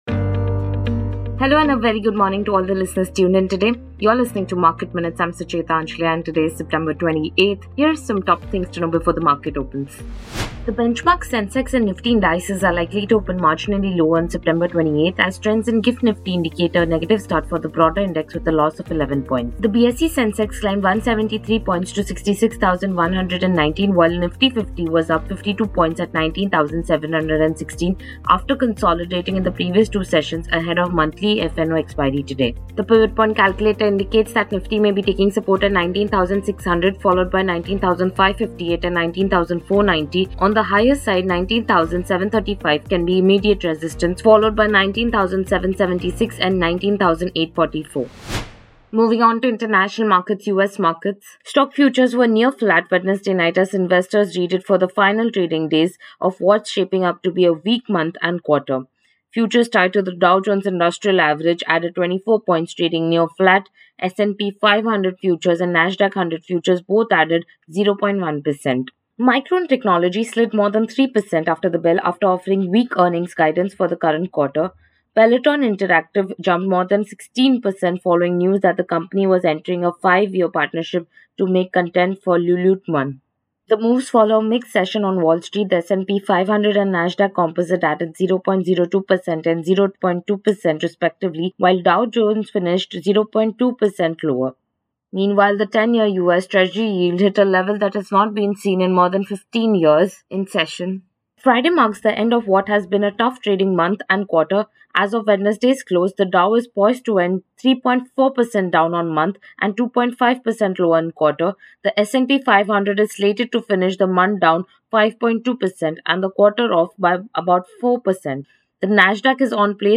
Market Minutes is a morning podcast that puts the spotlight on hot stocks, keys data points and developing trends